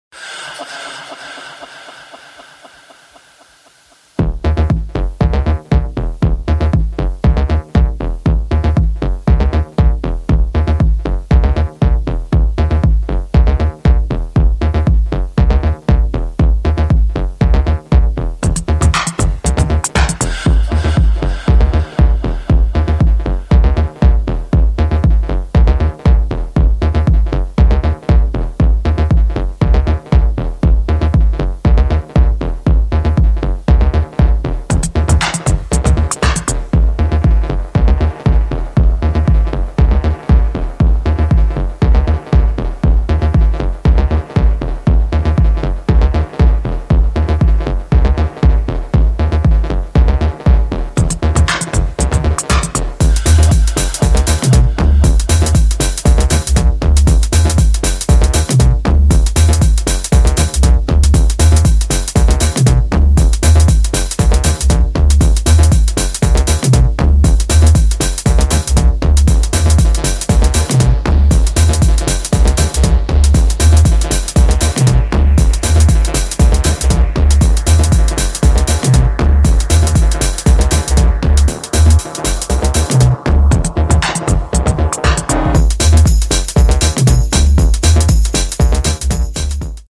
[ TECH HOUSE / BASS ]